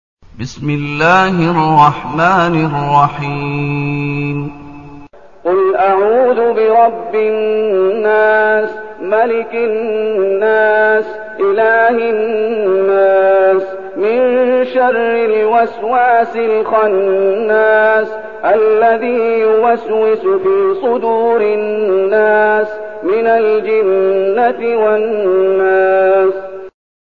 المكان: المسجد النبوي الشيخ: فضيلة الشيخ محمد أيوب فضيلة الشيخ محمد أيوب الناس